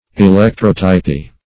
Meaning of electrotypy. electrotypy synonyms, pronunciation, spelling and more from Free Dictionary.
Search Result for " electrotypy" : The Collaborative International Dictionary of English v.0.48: Electrotypy \E*lec"tro*ty`py\, n. The process of producing electrotype plates.